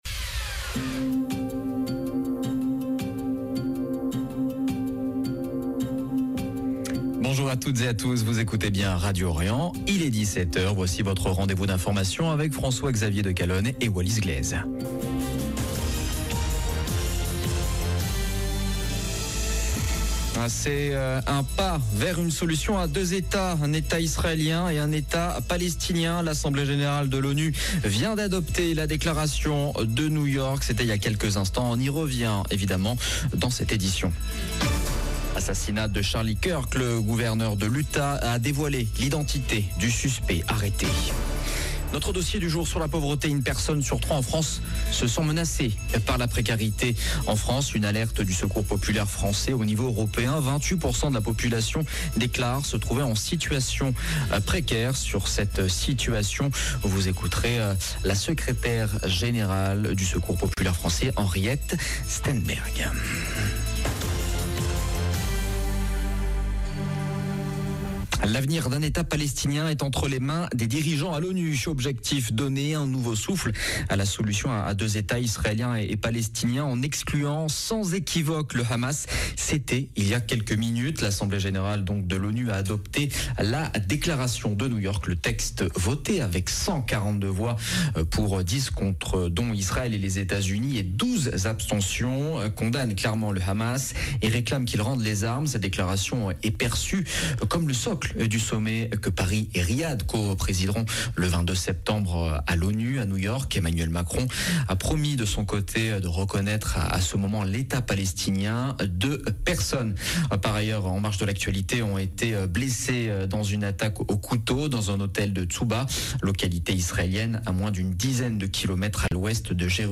Magazine d'information de 17H du 12 septembre 2025